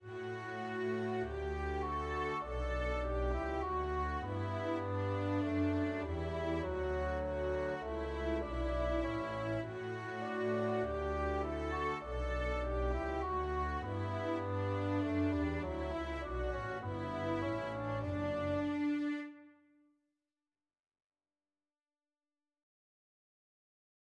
한편, 독주 바순은 마찬가지로 즉흥적인 느낌을 주는 대위법을 추가한다."[12]